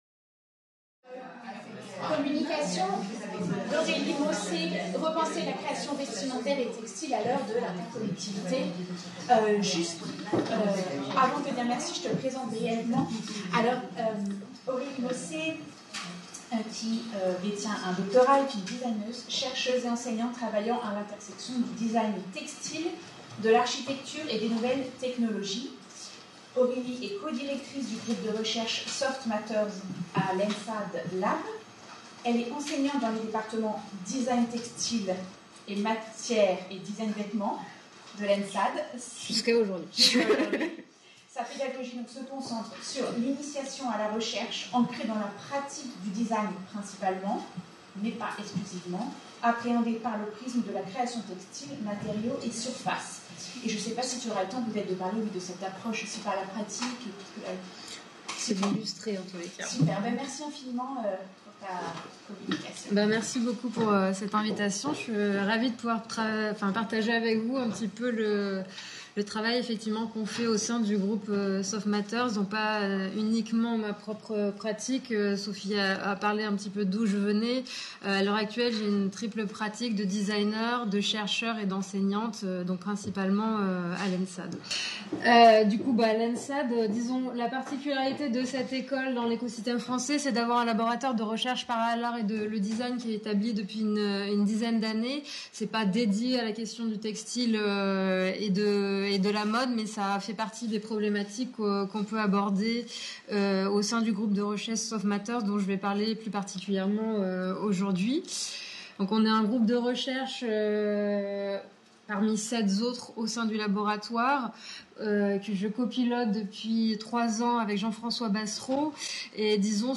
Séminaire sur la problématique de la mode et l'écologie